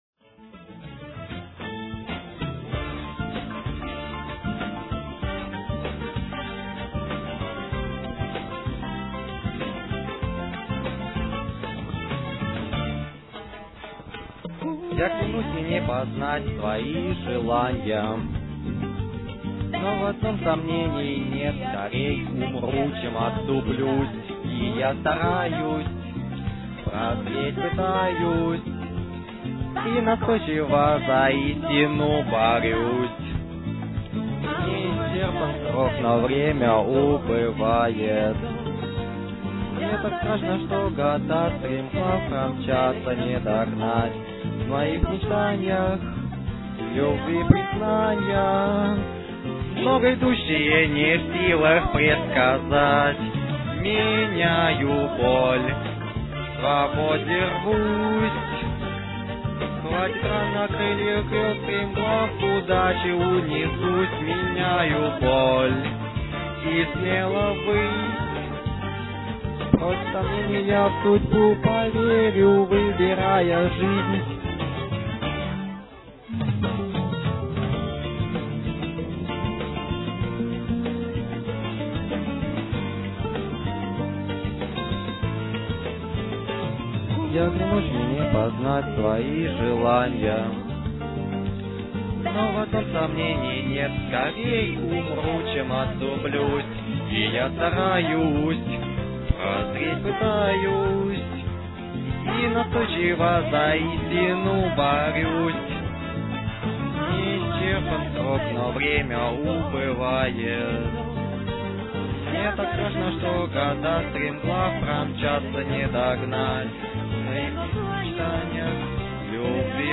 Песня соунд - трек к фильму
Мой перевод... моя озвучка...Пишите комментарии...